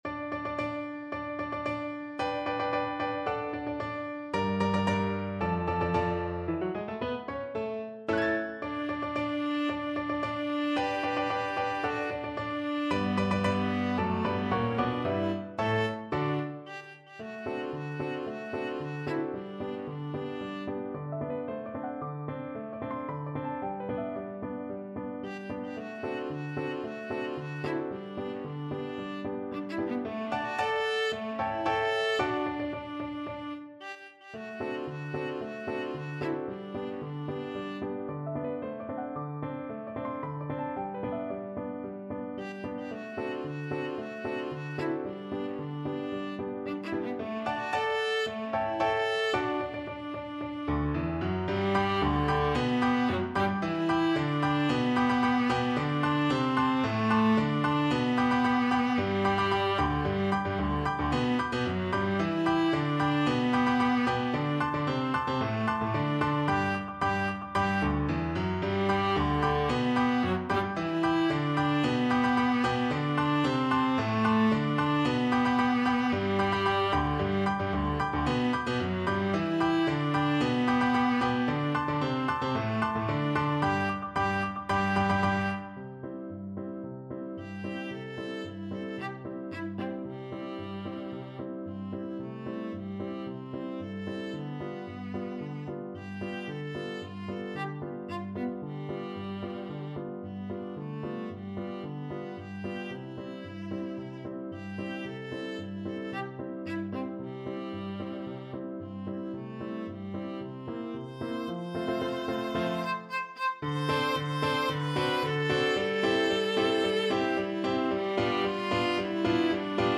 Classical Wagner, Josef Under the Double Eagle (Unter dem Doppeladler) Viola version
G major (Sounding Pitch) (View more G major Music for Viola )
~ = 112 Introduction
2/4 (View more 2/4 Music)
Viola  (View more Intermediate Viola Music)
Classical (View more Classical Viola Music)